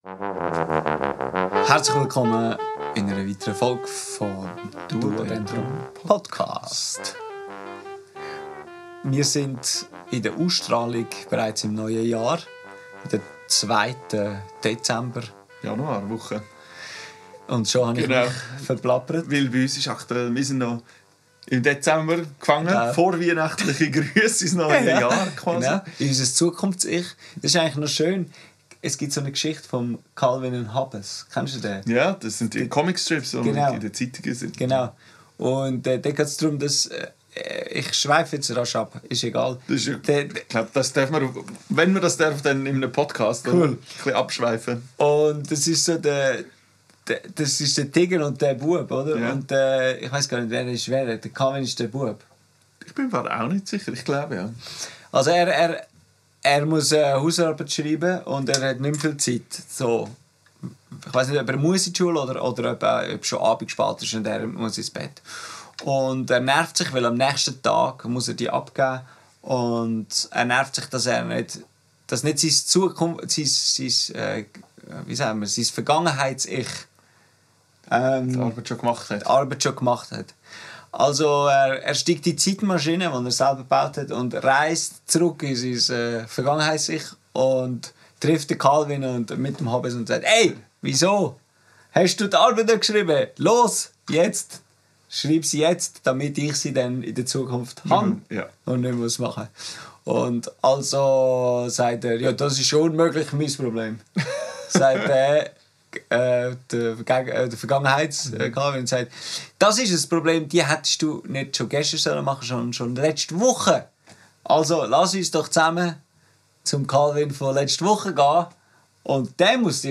im Atelier